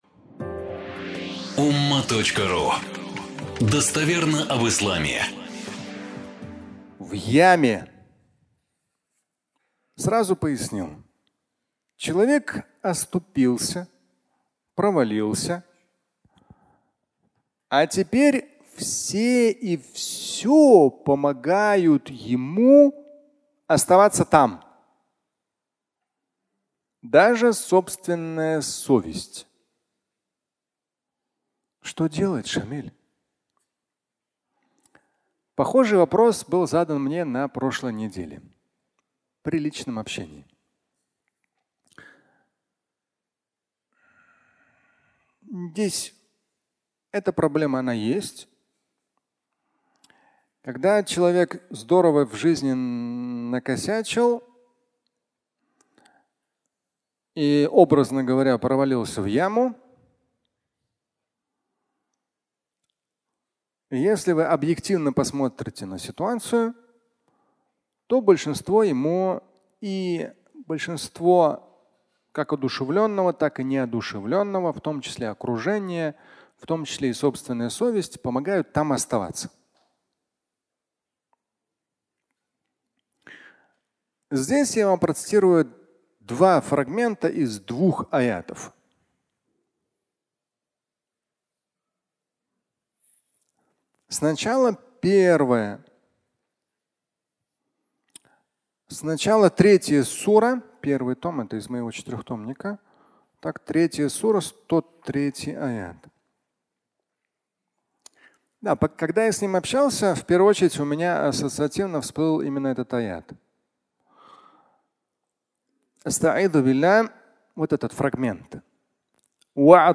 В яме (аудиолекция)